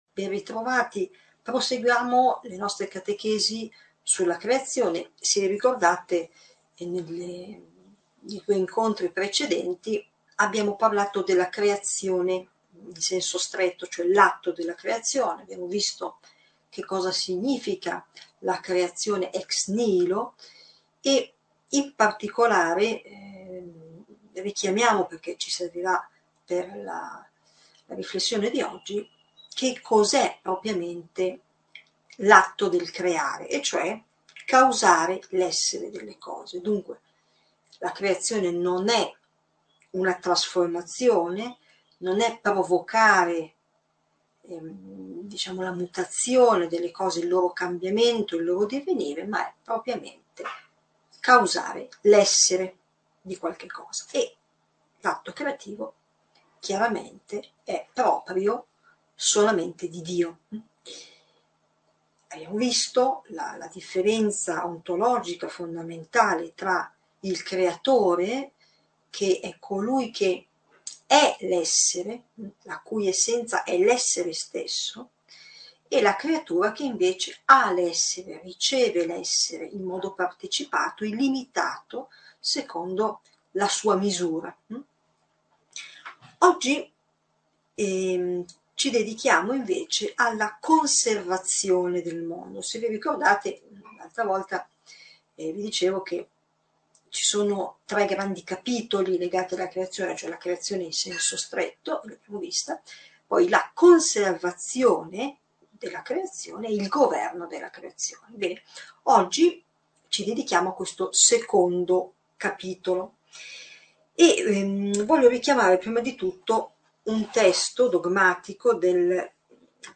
Catechesi adulti